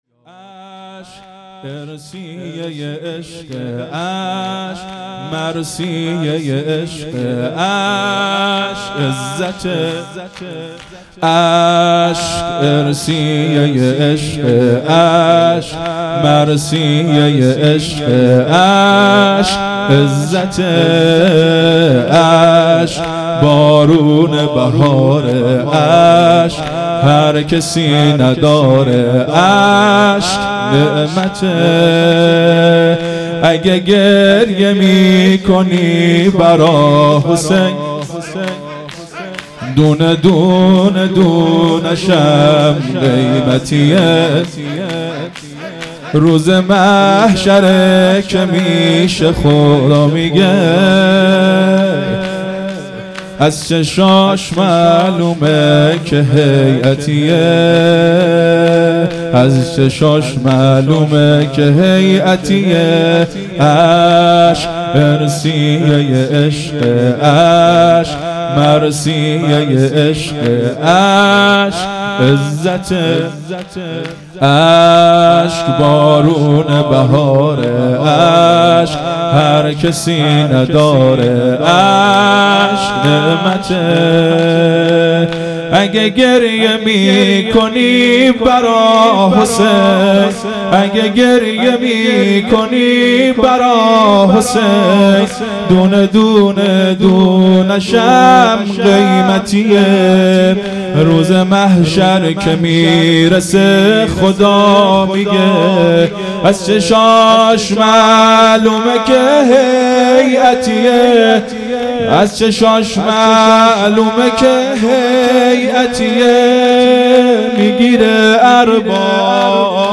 شور | اشک ارثیه عشقه مداح
محرم1442_شب هفتم